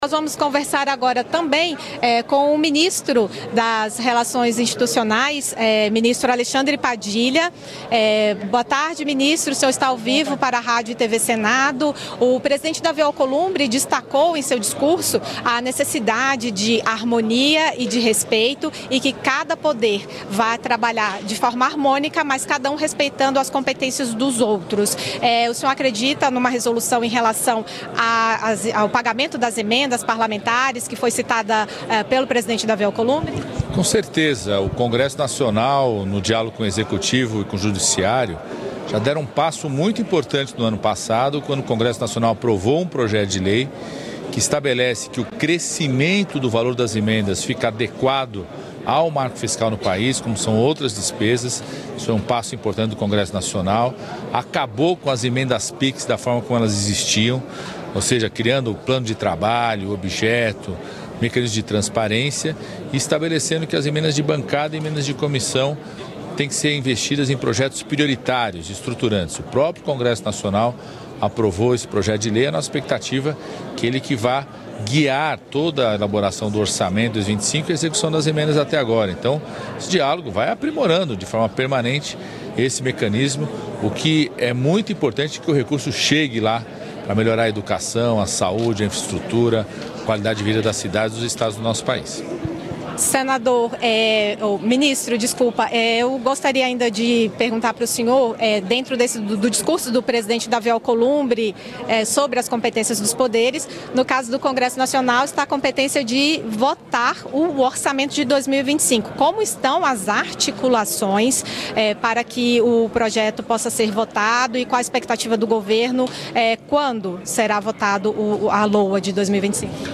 O ministro de Relações Institucionais, Alexandre Padilha, participou nesta segunda-feira (3) da sessão solene de abertura do Ano Legislativo, no Congresso Nacional.